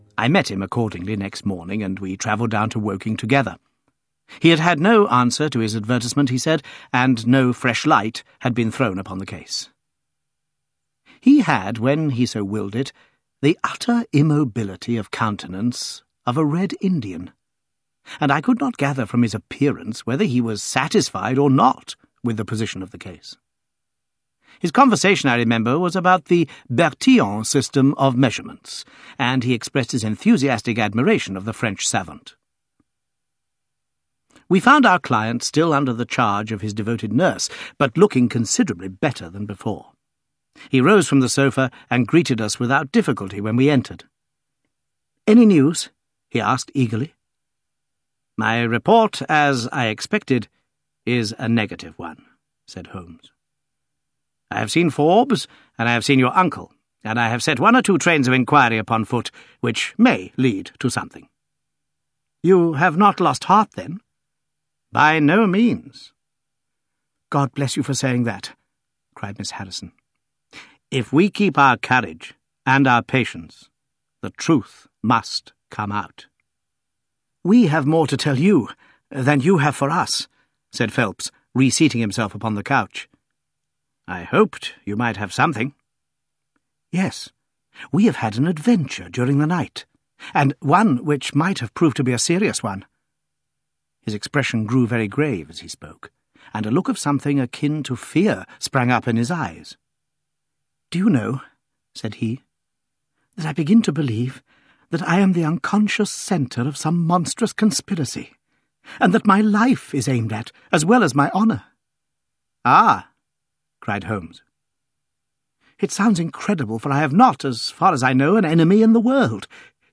The Adventures of Sherlock Holmes IV (EN) audiokniha
Ukázka z knihy